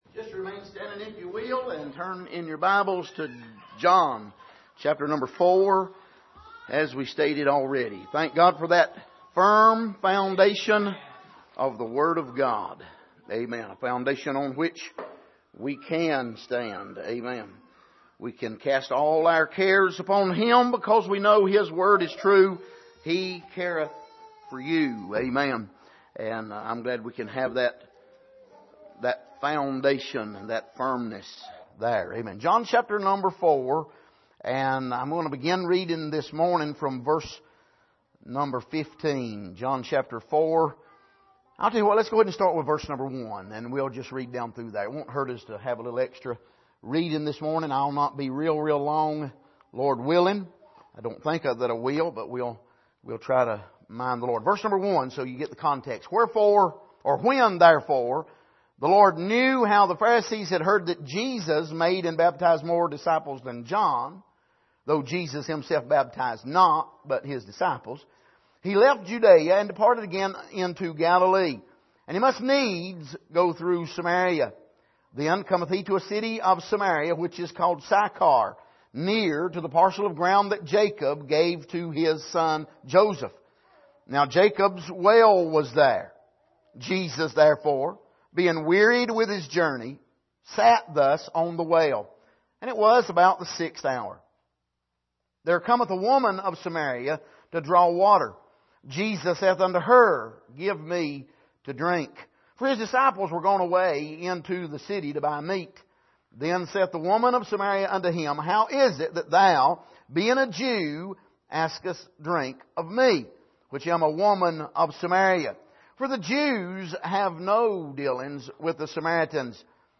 Passage: John 4:15-26 Service: Sunday Morning